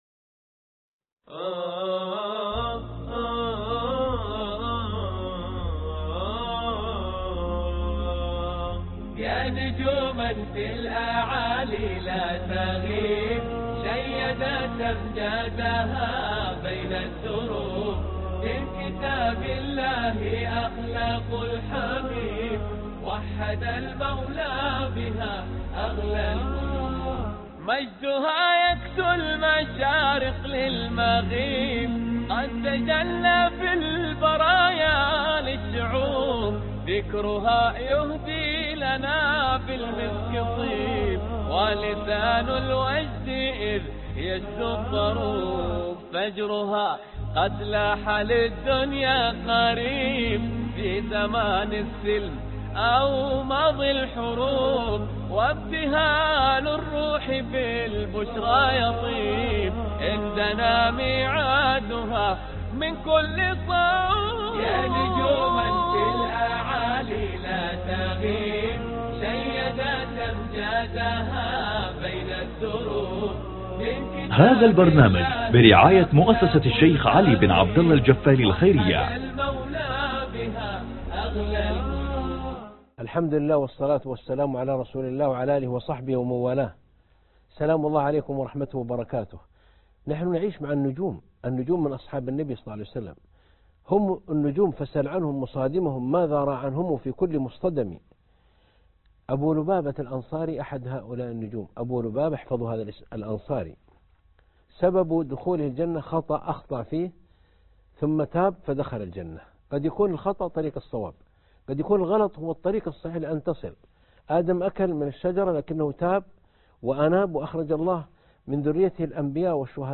عنوان المادة الدرس 20 (أبو لبابه الأنصاري رضي الله عنه) مع النجوم